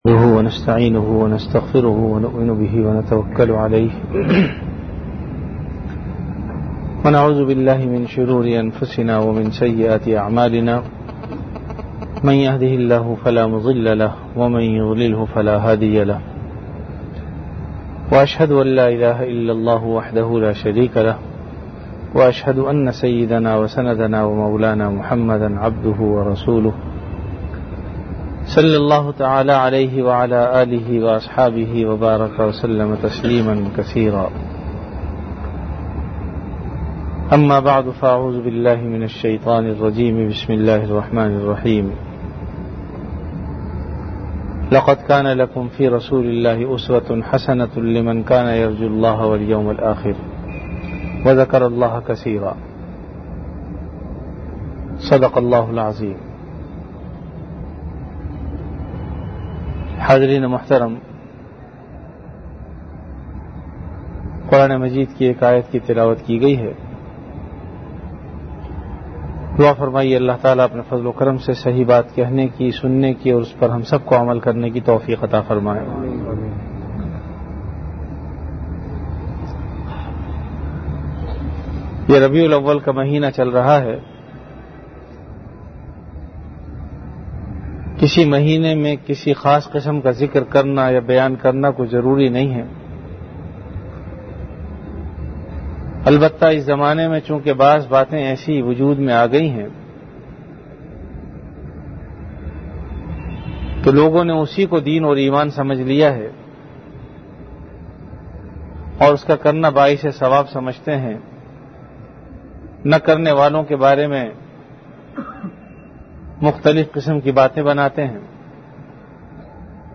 Bayanat · Jamia Masjid Bait-ul-Mukkaram, Karachi
After Isha Prayer